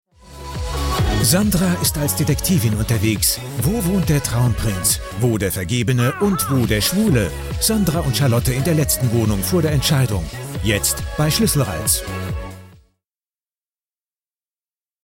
Profi Sprecher deutsch. Synchronsprecher, Werbesprecher. Sprecher für Hörspiele, Hörbücher, Imagefilm u.a.
Sprechprobe: eLearning (Muttersprache):